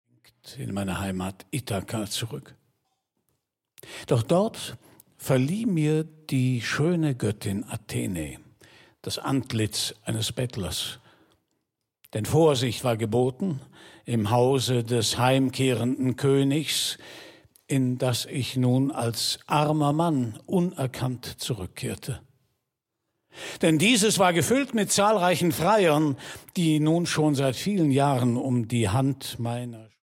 narration recorded 2010 at Domicil, Dortmund